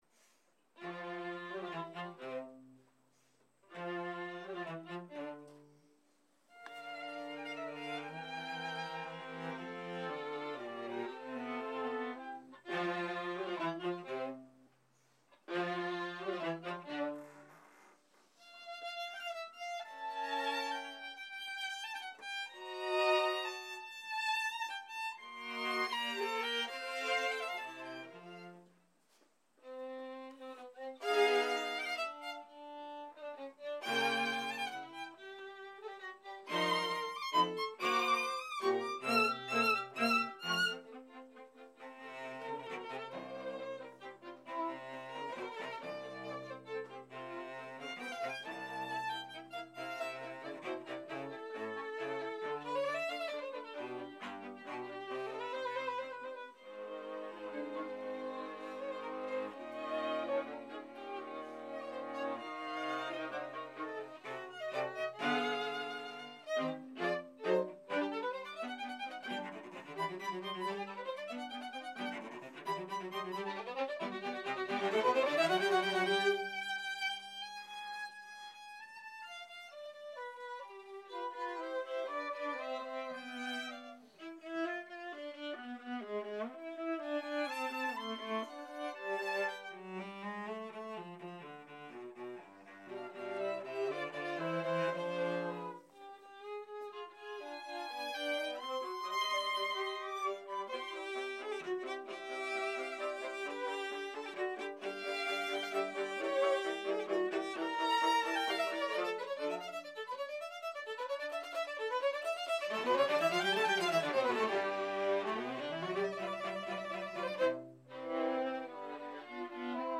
Allegro con brio